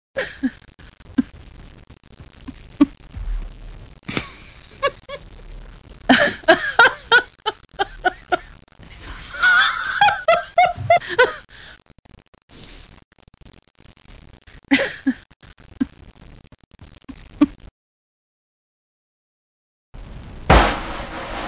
womanlau.mp3